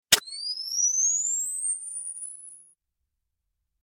Camera Flash Charging Sound Effect
Camera-flash-charging-sound-effect.mp3